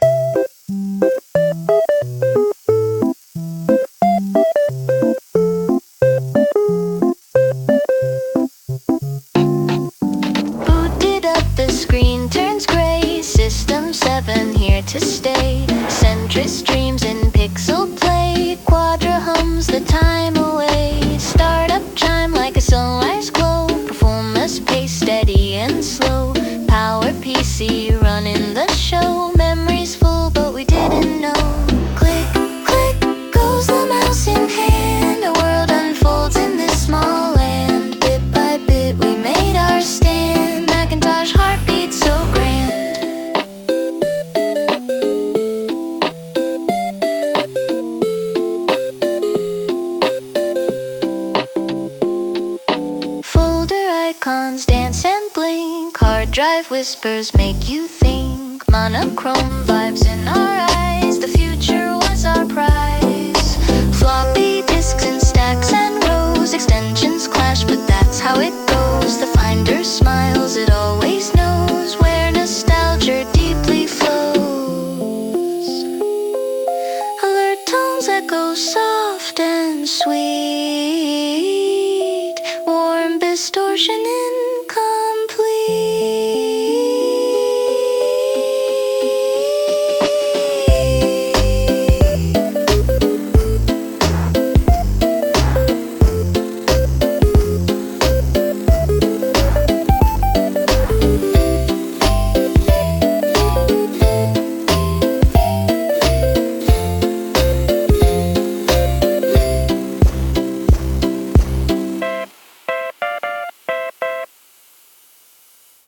3 Off Topic Discussion / System 7 song (AI)